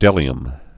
(dĕlē-əm)